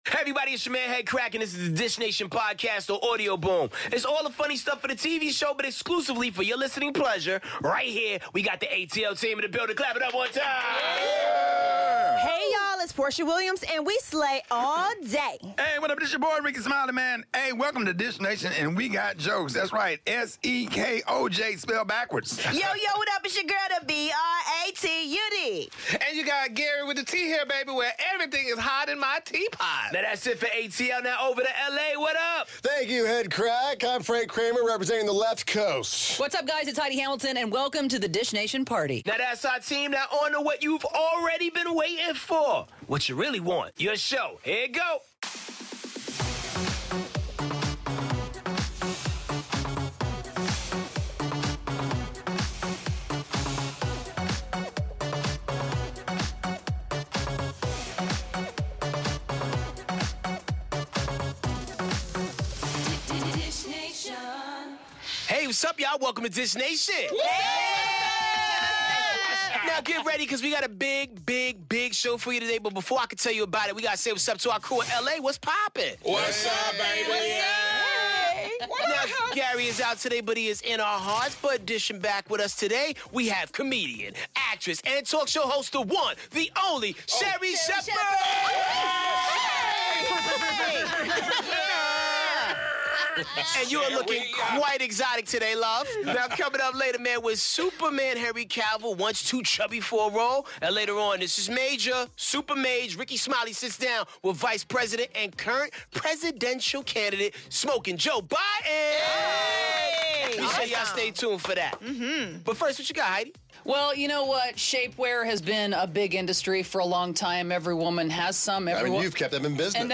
Rickey Smiley sits down with Democratic Presidential Candidate Joe Biden, Whoopi Goldberg is happily single and Sherri Shepherd is back in studio dishin’ on last night’s shocking 'The Masked Singer' elimination and MORE!